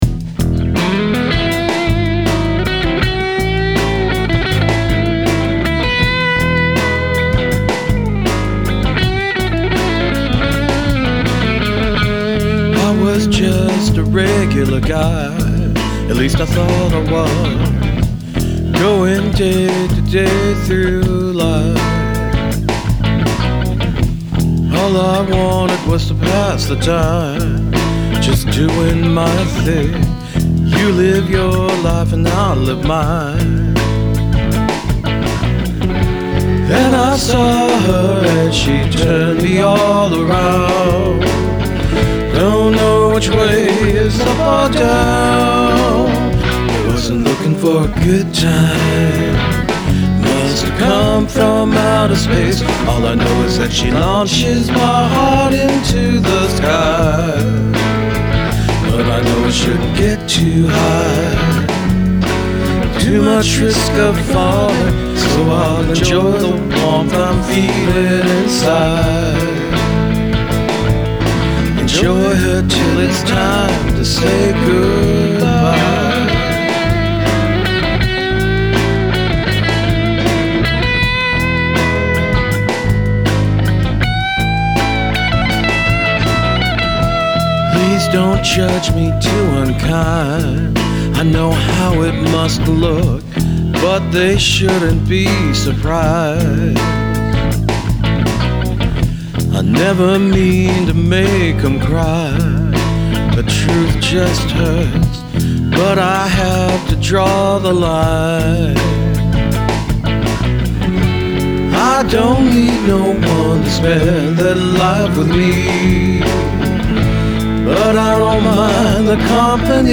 For the guitars, I added just a little high-end boost to make them stand out a bit more, but I kept their levels pretty much the same.
Katie May was set to the middle position (both pickups), but in single coil mode so I could get a bit of that Strat Position 4 jangly sound with the dirt.
But being a Plexi-style amp, cranked up, this amp has a big sound, and on the “lead” track, I had to make sure I was doing a lot of palm muting to tame the overdrive a bit.
Bass: Ibanez G-10